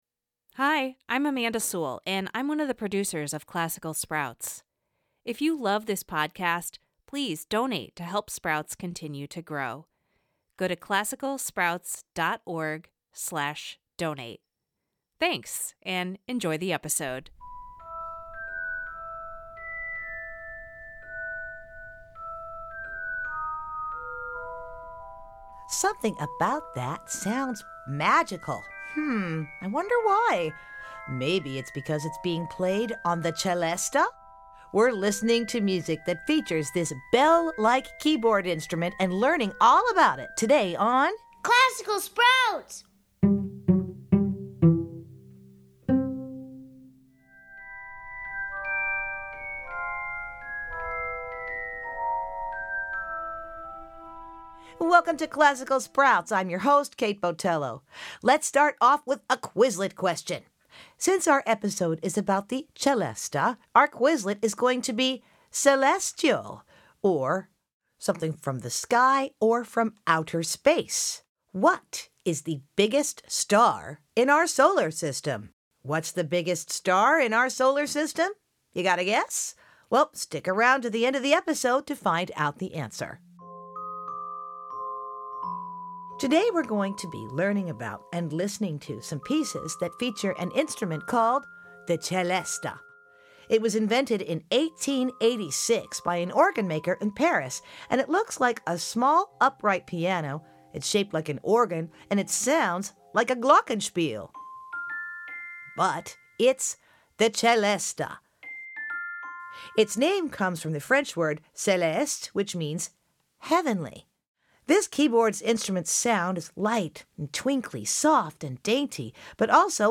It looks like a small upright piano, but is shaped like an organ and sounds like a glockenspiel... It’s the Celesta! We’re learning about and listening to music that features the celesta, a bell-like keyboard instrument that is often associated with magical-sounding music this week on Classical Sprouts.